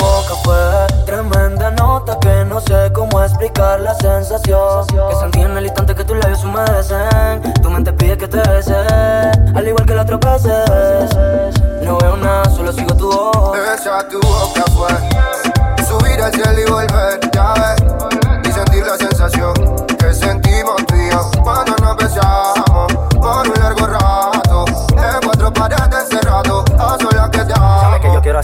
Genere: latin pop, latin urban, reggaeton, remix